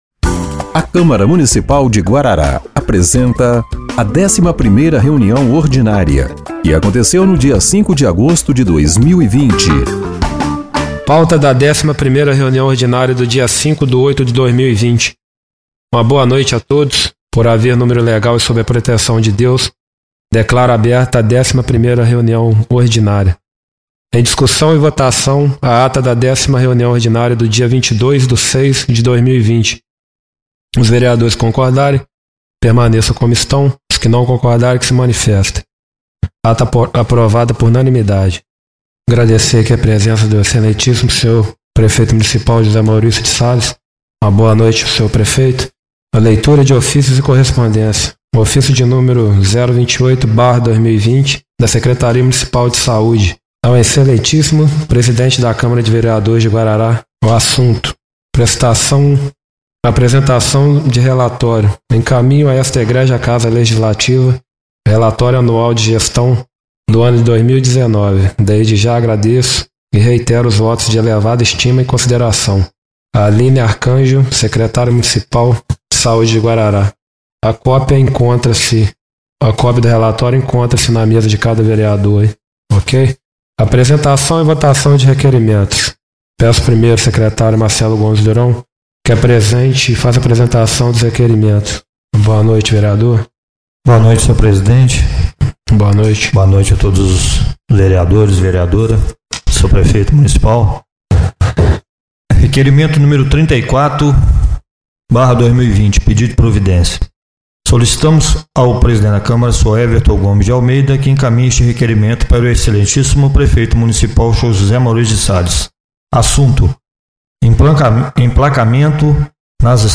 11ª Reunião Ordinária de 05/08/2020